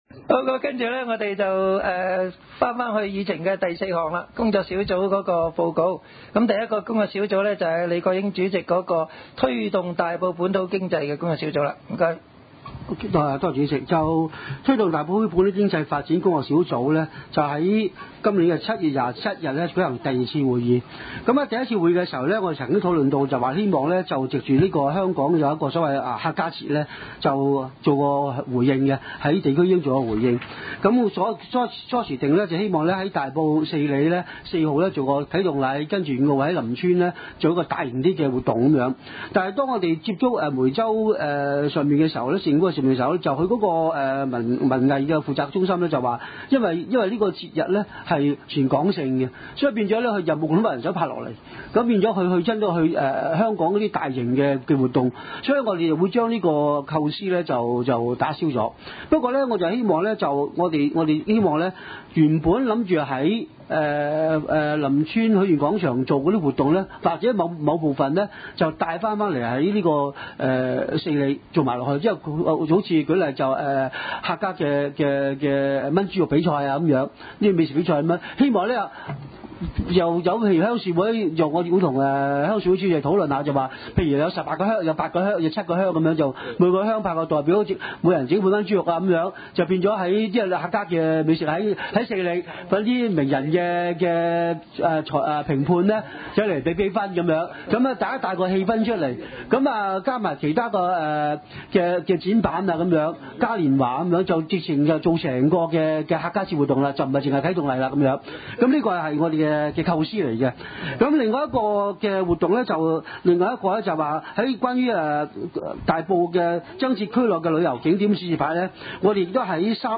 漁農工商、旅遊及文娛康體委員會2010年第五次會議
地點：大埔區議會秘書處會議室